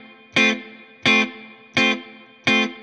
DD_StratChop_85-Fmaj.wav